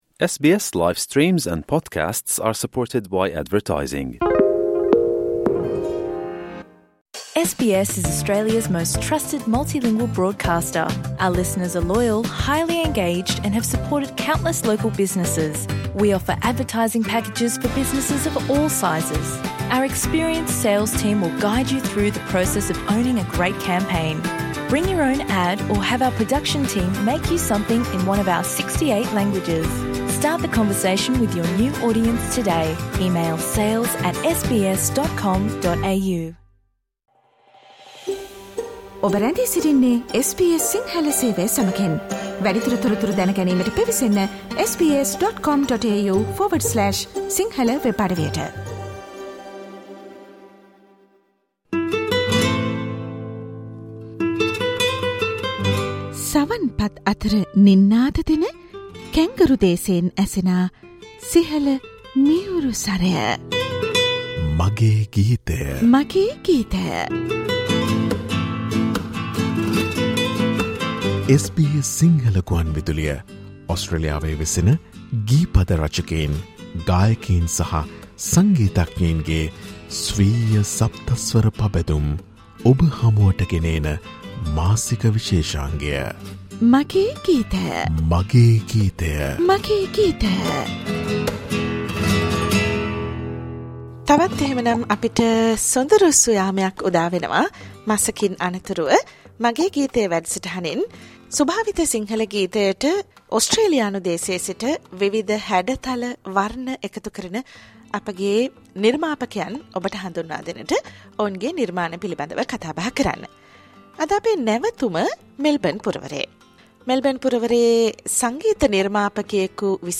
SBS Sinhala monthly musical program